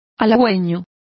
Complete with pronunciation of the translation of promising.